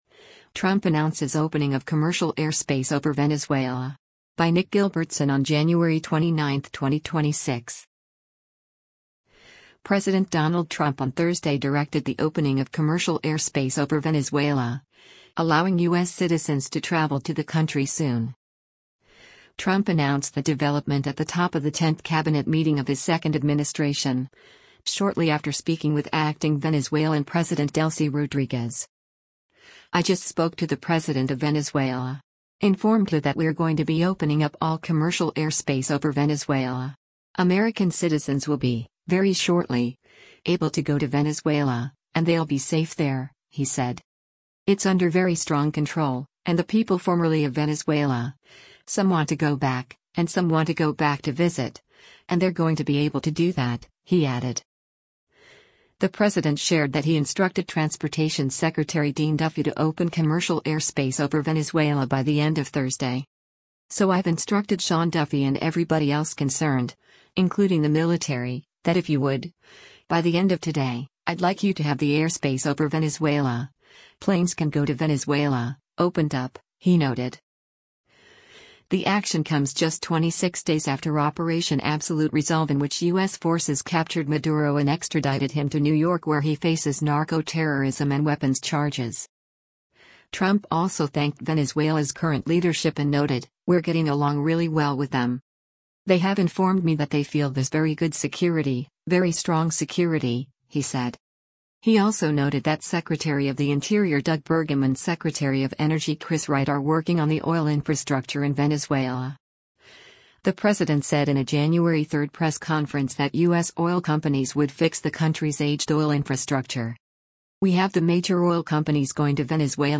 US President Donald Trump speaks during a cabinet meeting in the Cabinet Room of the White